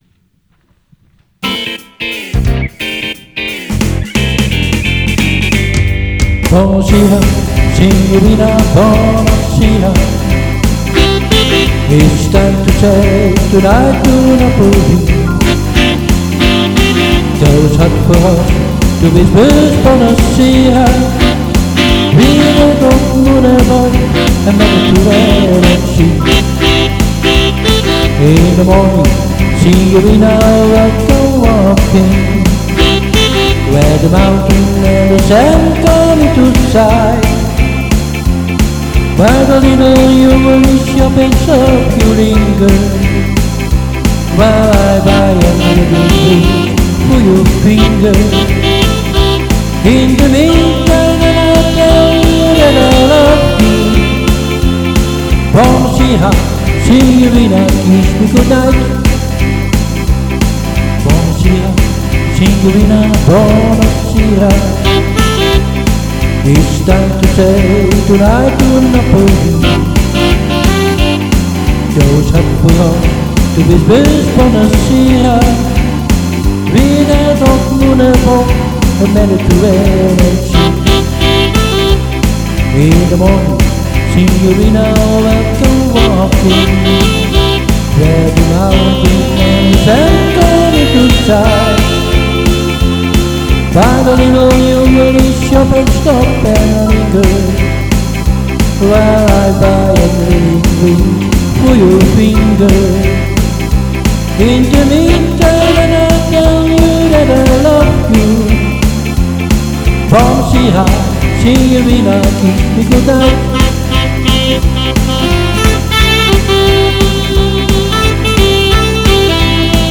One-Man-Band
Zanger Toetsenist